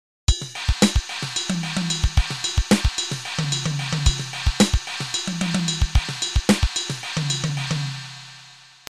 The snare is totally straight, 2 and 4 with no ghosting.
This was one of the first grooves in 7 that I created after not playing for a year or two. The ride pattern alternates between cymbals and takes two bars to resolve — that is, if you start with 1 on the bell, the following 1 will be on the china because there are 7 beats to the bar.
sevengroove.mp3